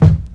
KICK_NO_FURY.wav